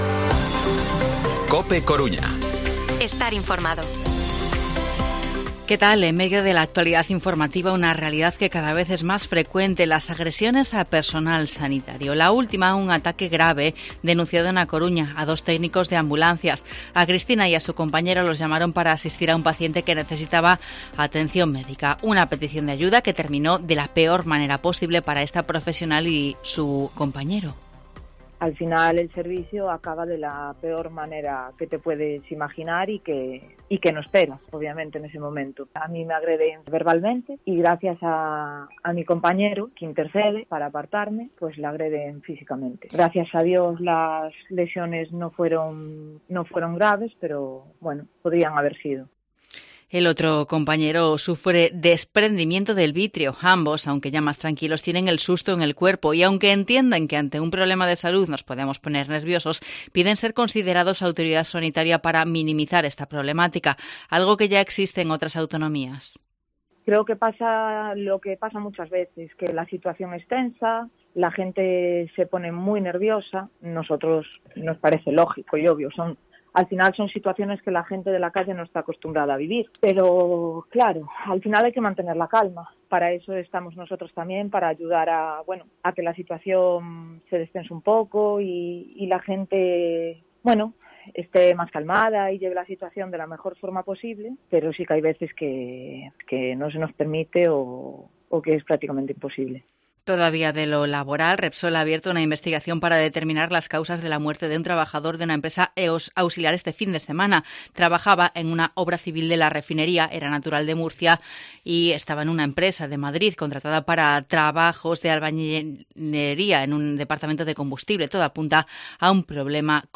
Informativo Mediodía COPE Coruña lunes, 24 de octubre de 2022 14:20-14:30